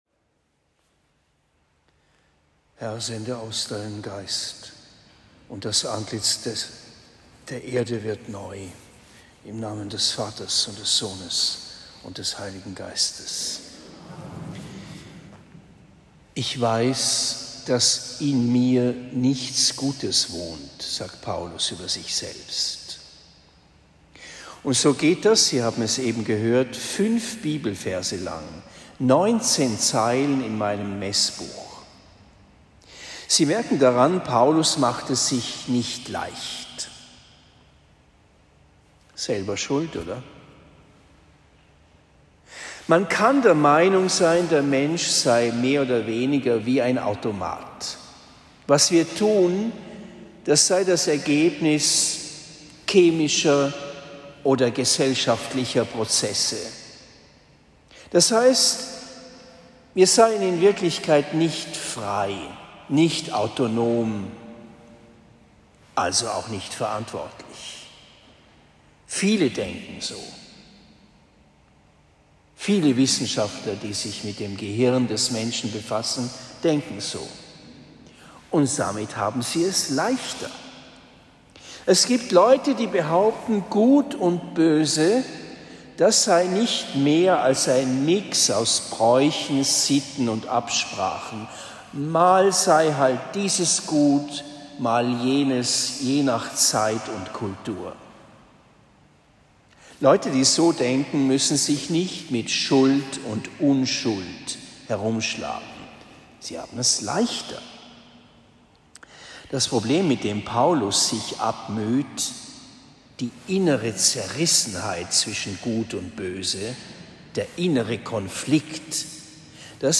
Predigt in Oberndorf am 27. Oktober 2023